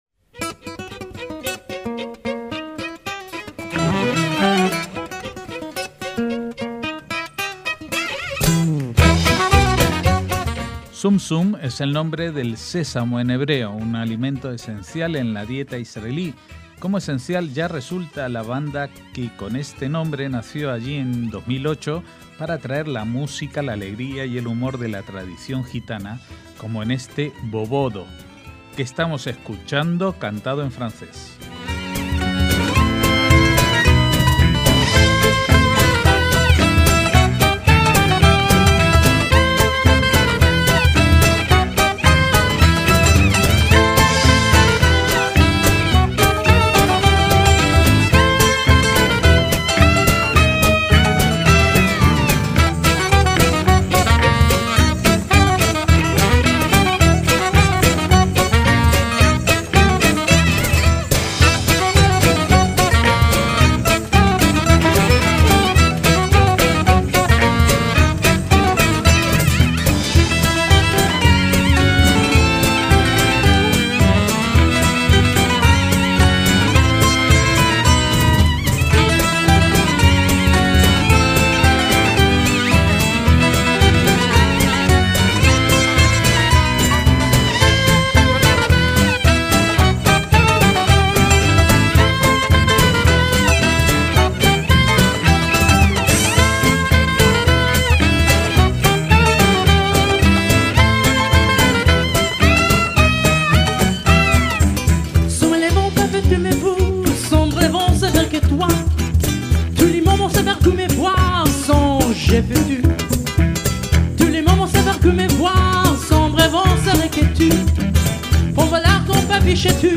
MÚSICA ISRAELÍ
saxo
bouzuki, el laúd griego
percusiones árabes
voz de contratenor operístico